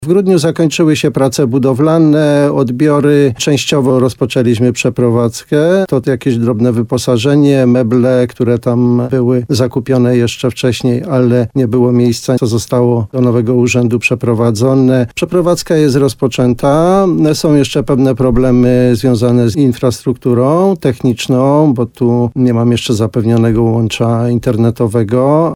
Gość programu Słowo za Słowo na antenie RDN Nowy Sącz podkreślał, że mimo wszystko przeprowadza ruszyła.